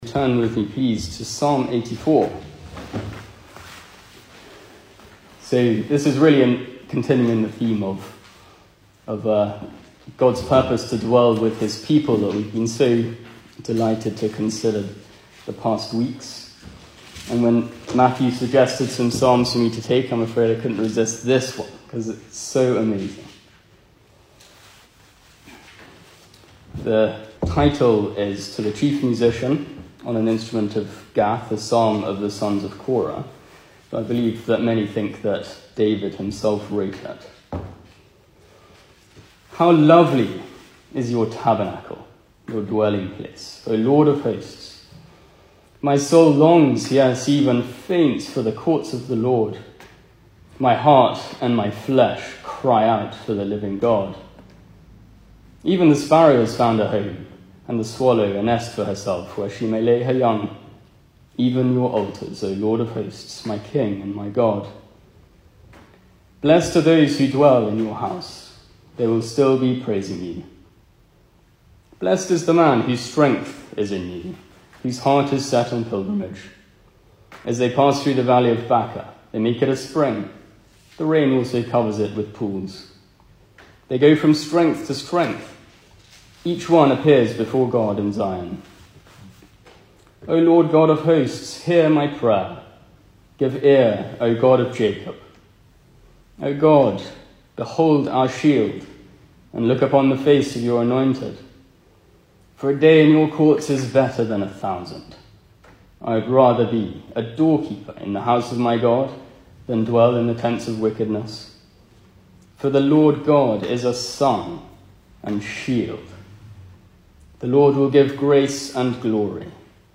Service Type: Weekday Evening
Single Sermons